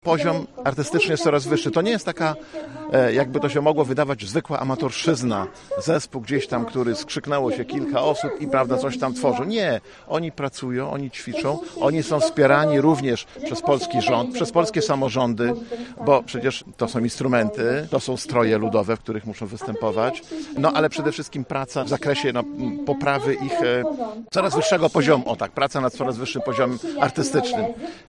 Pracę artystów docenił starosta łomżyński, Lech Marek Szabłowski: